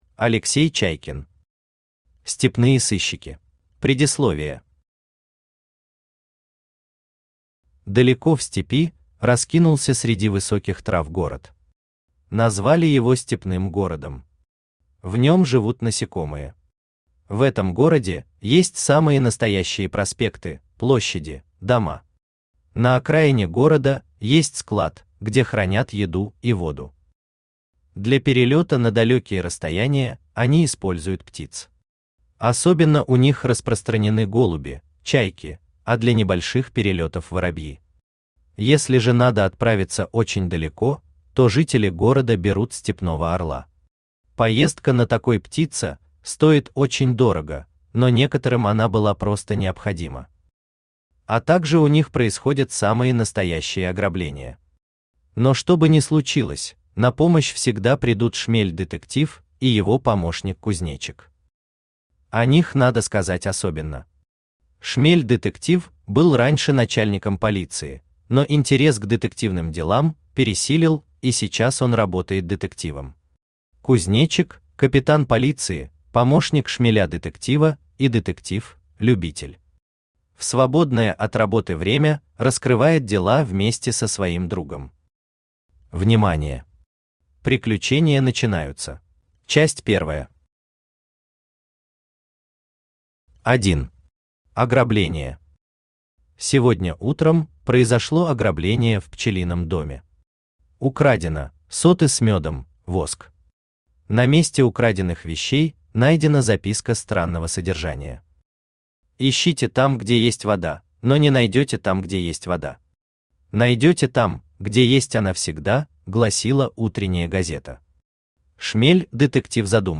Аудиокнига Степные сыщики | Библиотека аудиокниг
Aудиокнига Степные сыщики Автор Алексей Сергеевич Чайкин Читает аудиокнигу Авточтец ЛитРес.